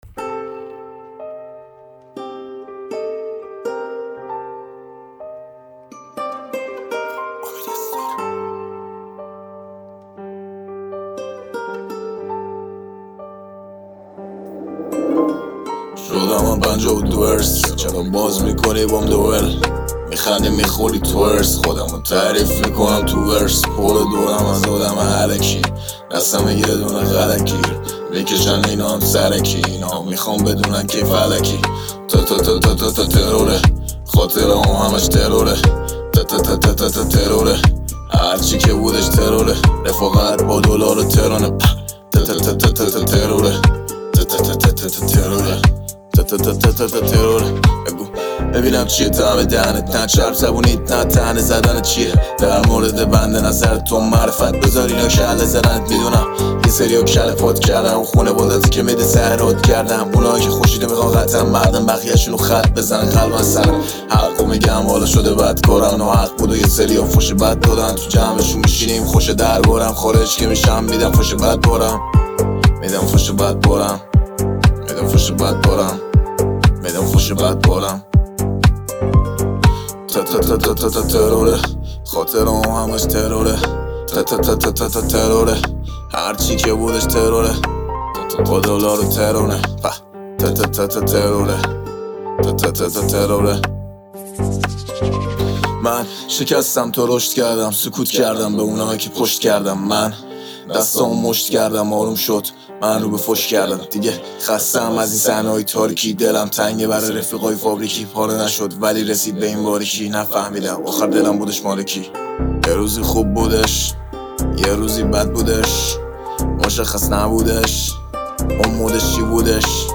موسیقی رپ ایران